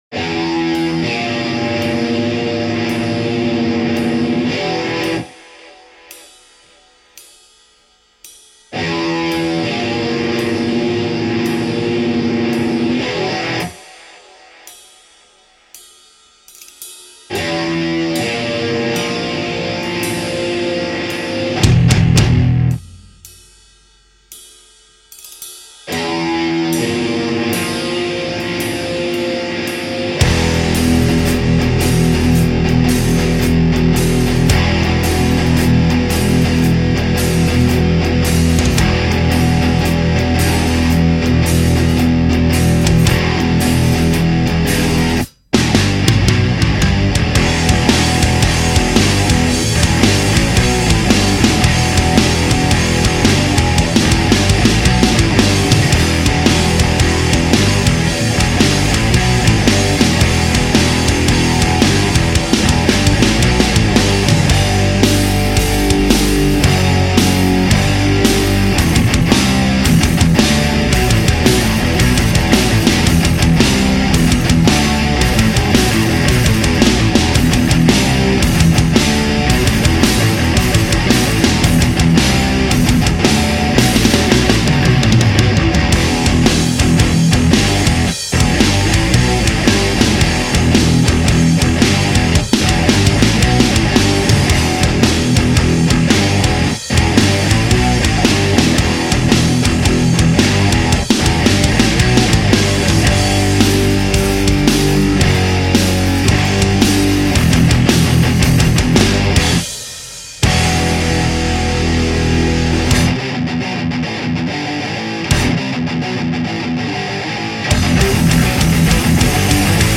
EstiloThrash Metal